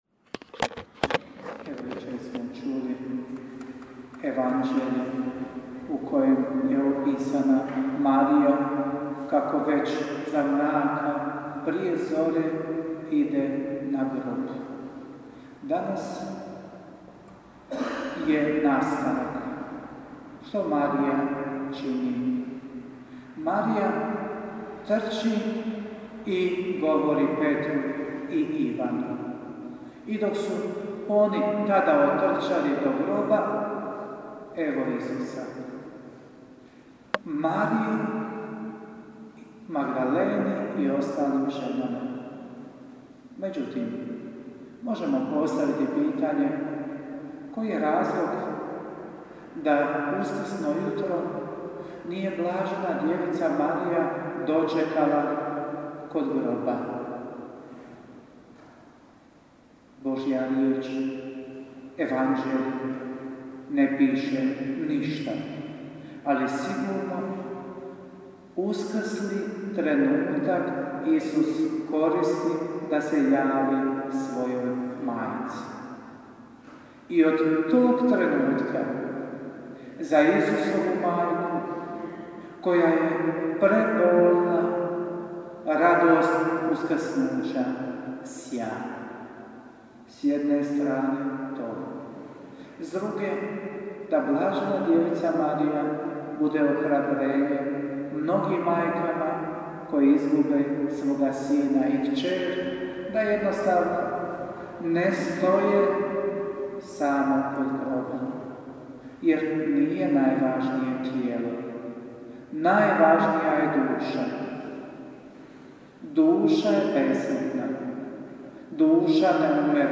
USKRSNI PONEDJELJAK PROPOVIJED: